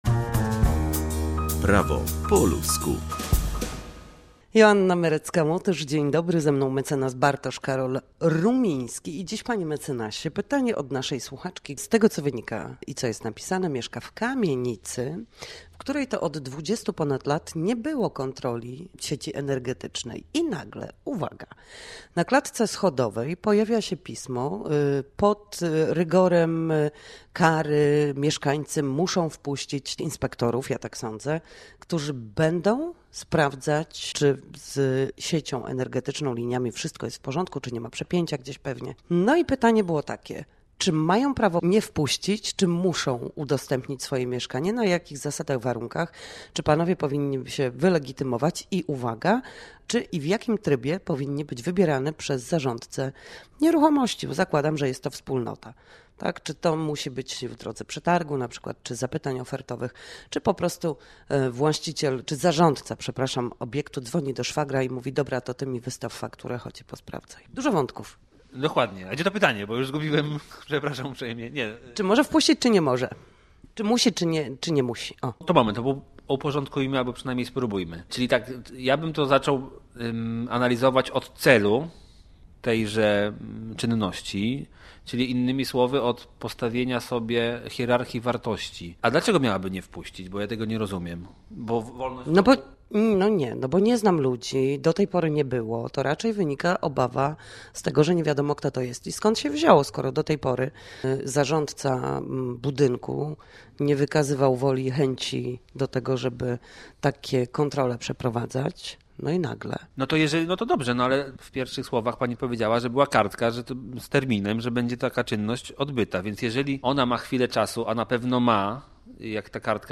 W każdy piątek o godzinie 7:20 i 13:40 na antenie Studia Słupsk przybliżamy państwu meandry prawa. Nasi goście, prawnicy, odpowiadają na jedno pytanie dotyczące zachowania w sądzie i podstawowych zagadnień prawniczych.